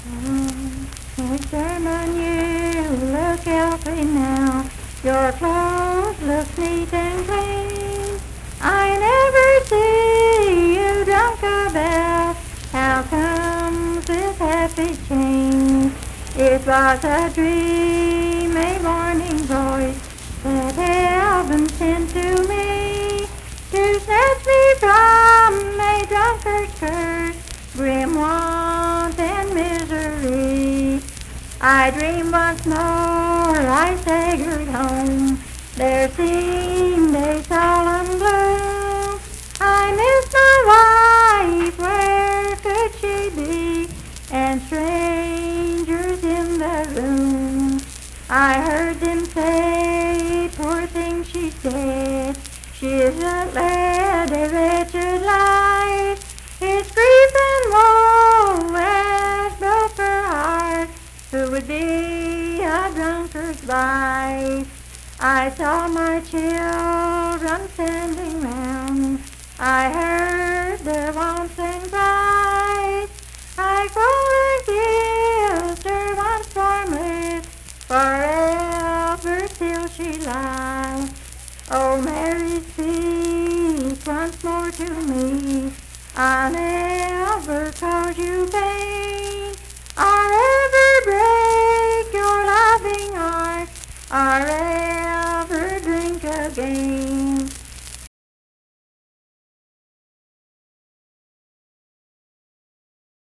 Unaccompanied vocal music
Voice (sung)
Roane County (W. Va.), Spencer (W. Va.)